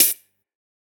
Index of /musicradar/ultimate-hihat-samples/Hits/ElectroHat B
UHH_ElectroHatB_Hit-03.wav